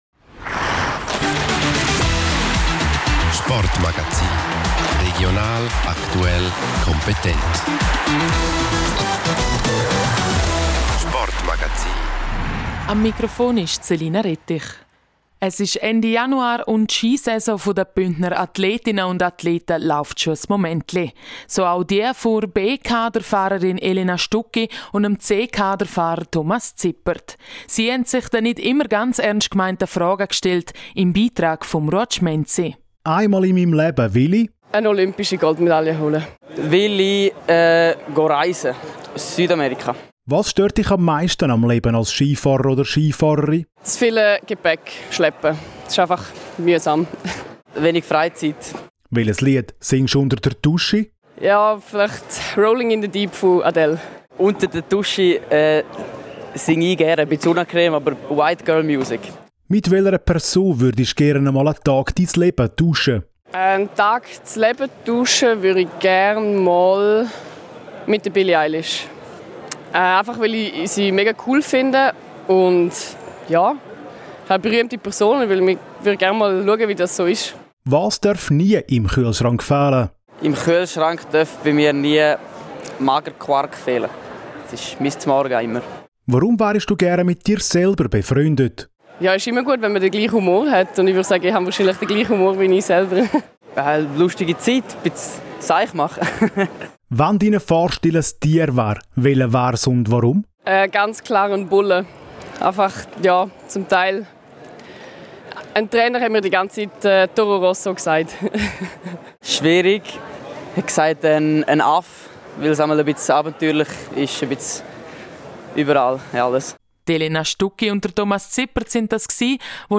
Lustiges Interview Teil 3: Bündner Ski-Cracks beantworten die etwas anderen Fragen